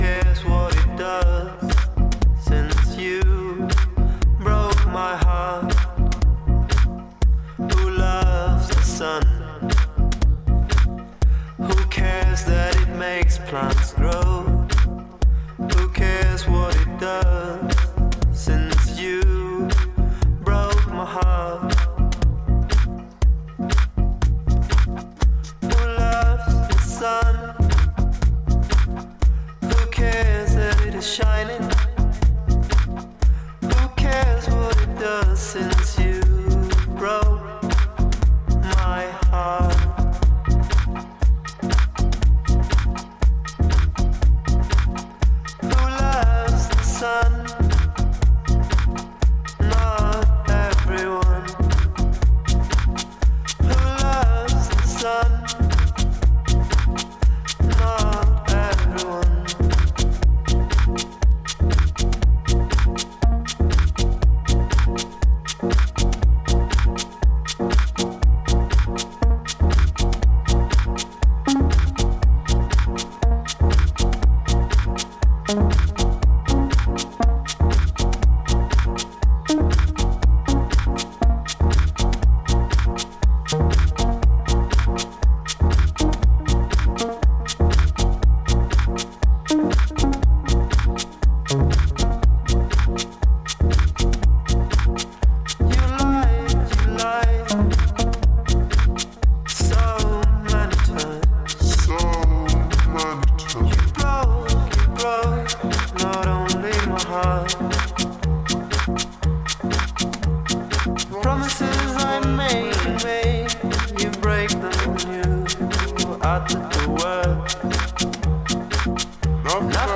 Excellent unique groovin / summer tech house anthem